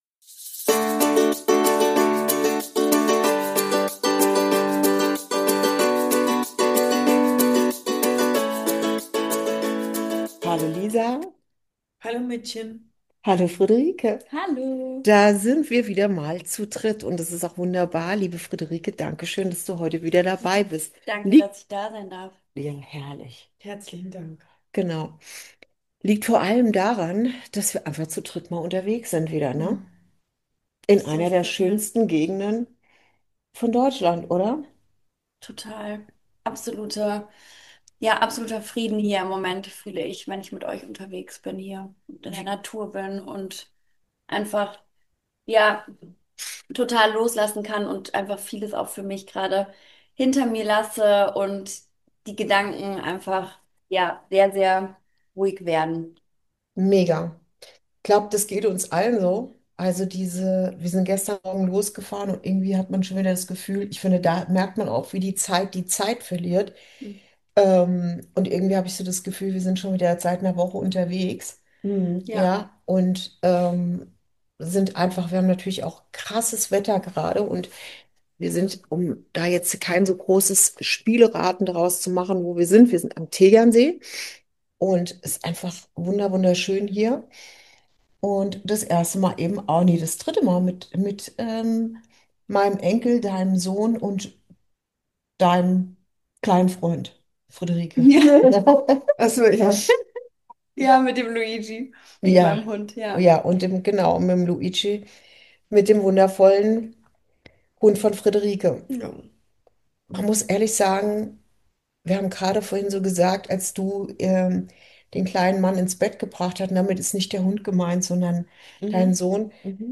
062: Nicht nachdenken, einfach weitergehen ~ Inside Out - Ein Gespräch zwischen Mutter und Tochter Podcast